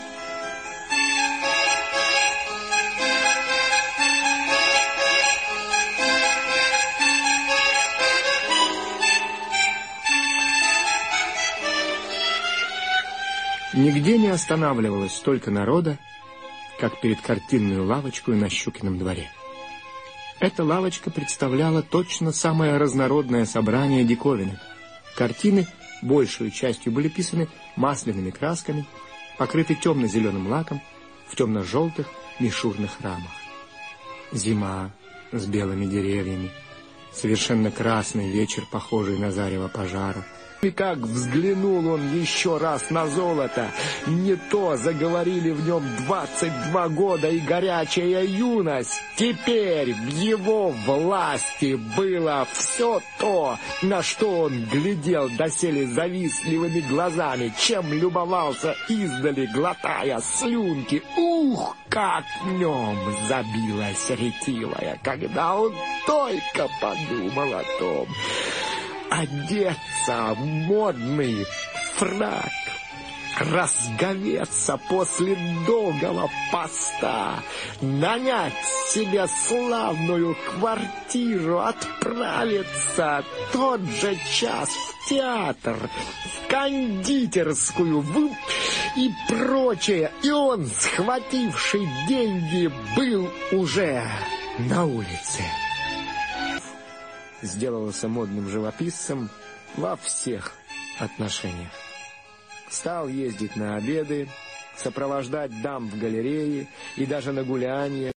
друзья, подскажите пожалуйста, что за мелодия звучит фоном в литературных чтениях на "радио России" Н.В. Гоголь "портрет" читает Александр Лыков?
gogol-melodiya~1.mp3